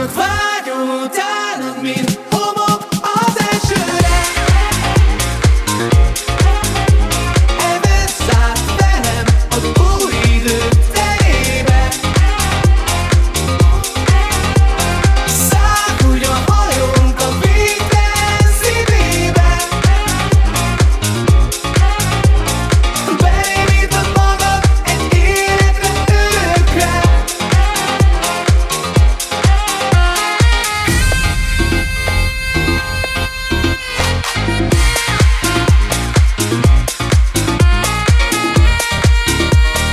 Universal Disco FunkHouse Extended Version